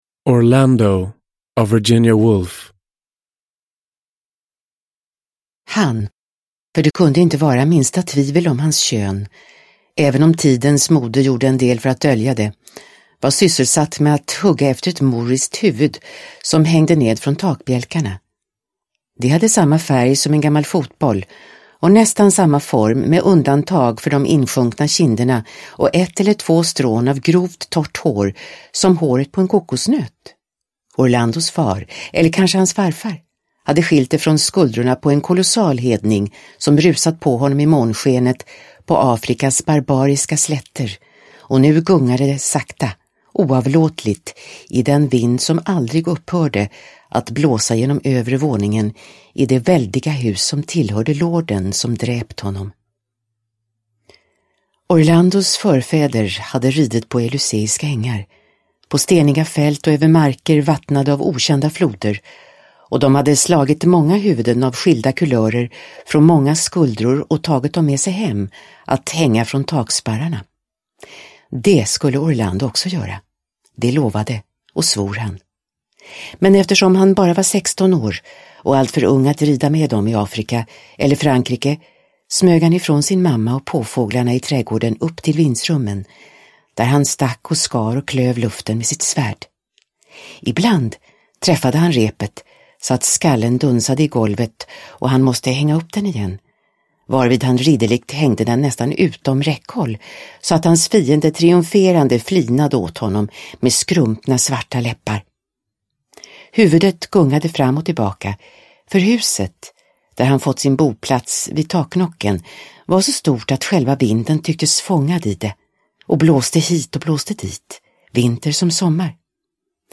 Orlando – Ljudbok – Laddas ner
Uppläsare: Ewa Fröling